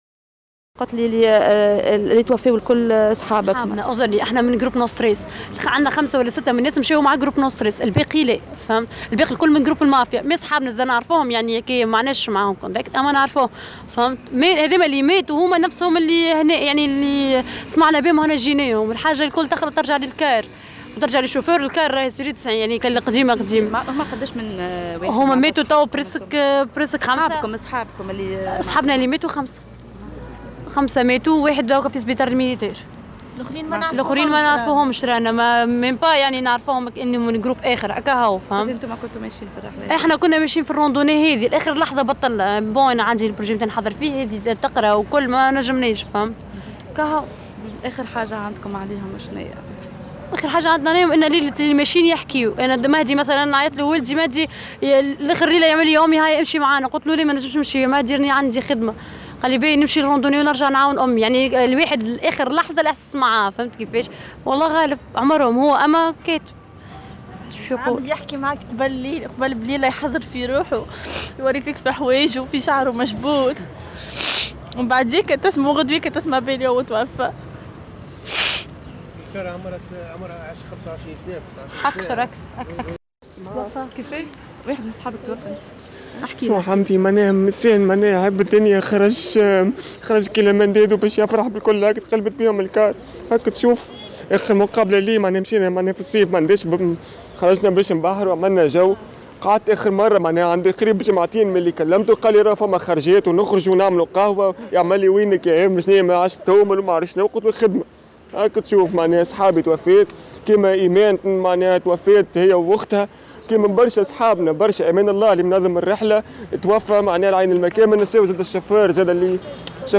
بحرقة كبيرة، تحدث أصدقاء عدد من ضحايا حادث المرور الذي جدّ أمس بمنطقة عين السنوسي التابعة لمعتمدية عمدون من ولاية باجة، عن آخر اللحظات التي جمعتهم بهم قبل الفاجعة.